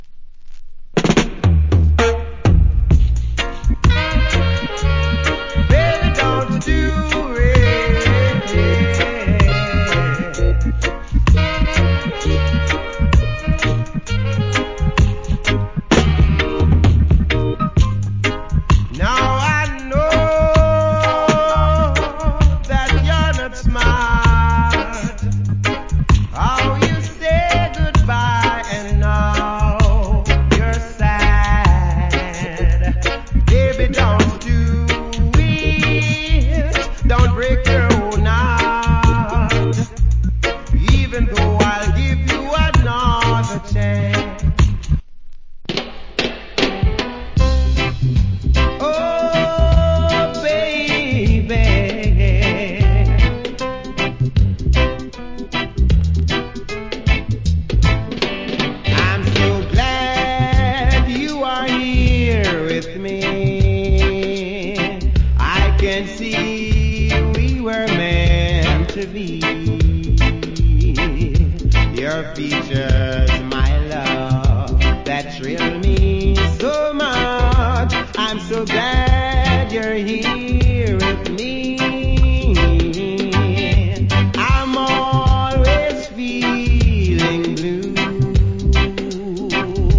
Cool Roots Vocal.